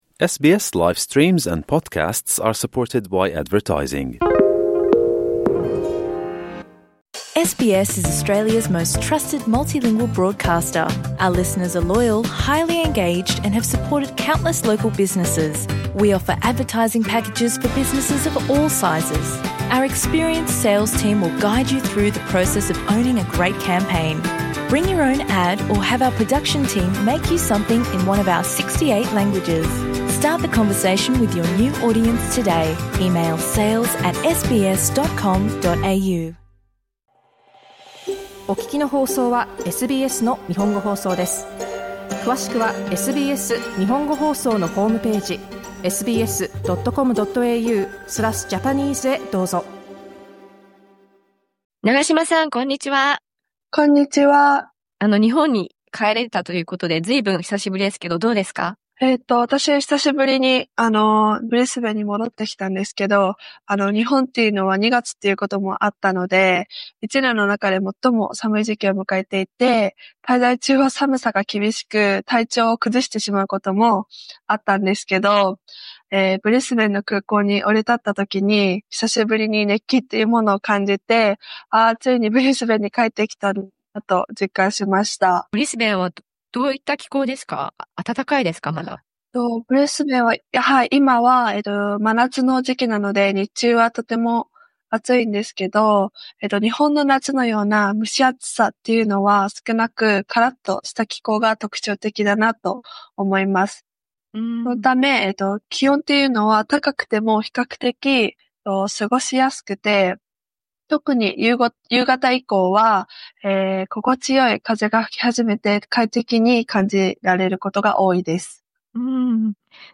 国内各地の話題や情報をお伝えするコーナー、「オーストラリアワイド」。